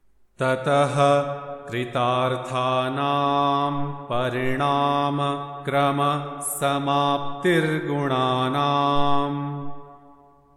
Sutra Chanting